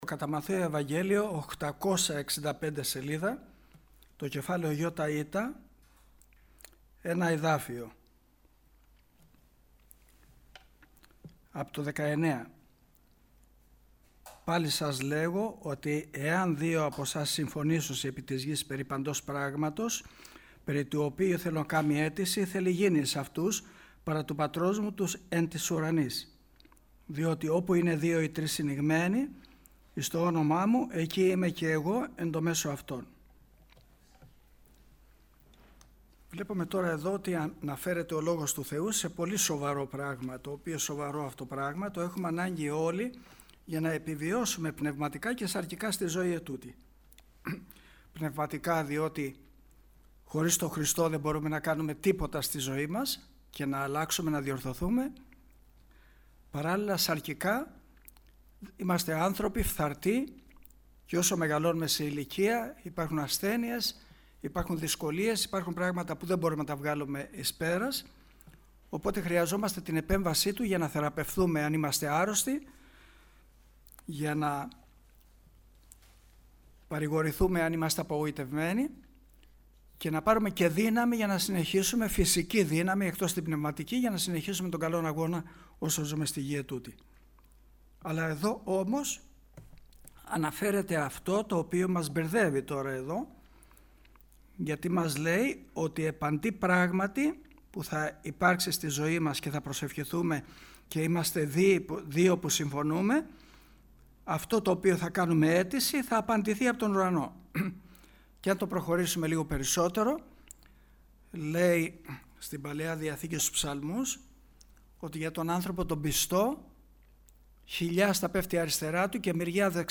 Κηρυγμα_Ευαγγελιου